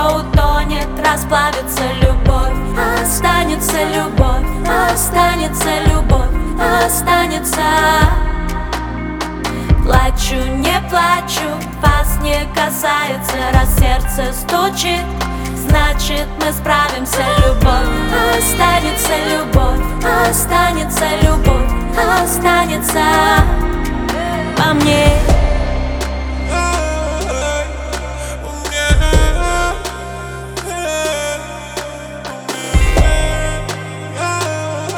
Pop Alternative Indie Pop